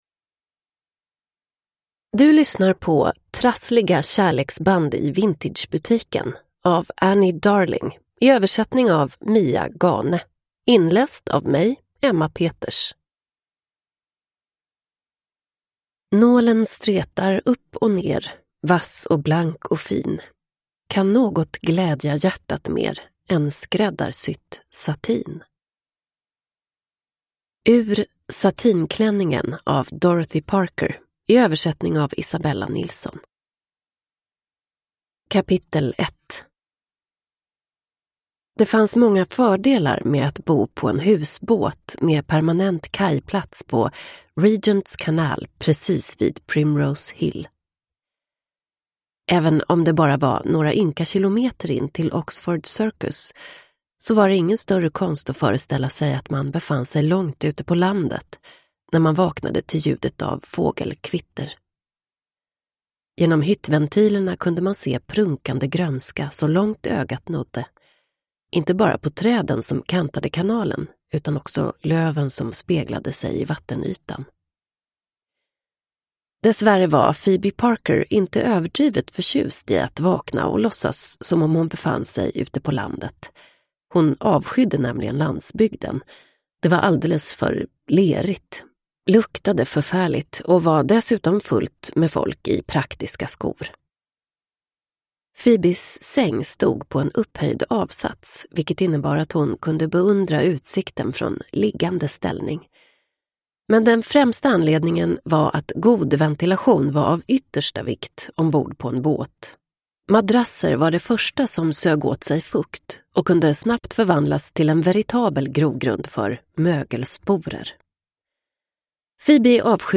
Trassliga kärleksband i Vintagebutiken (ljudbok) av Annie Darling